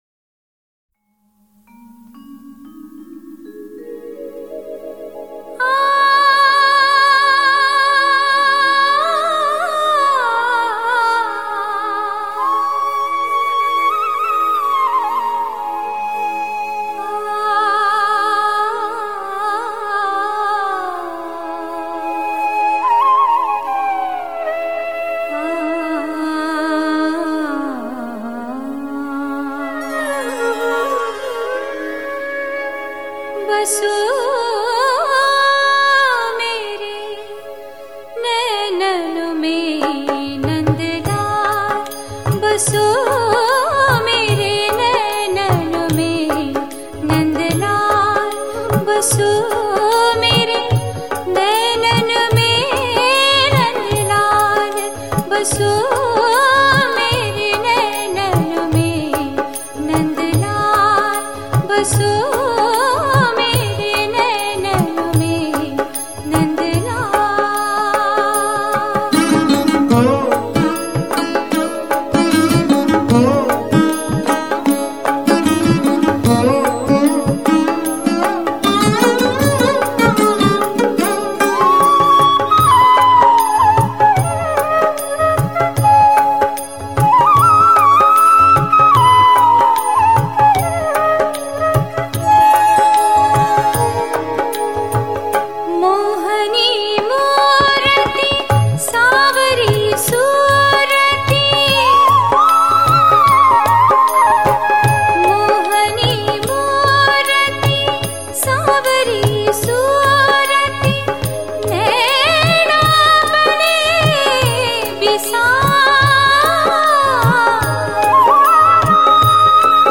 » Bhakti Songs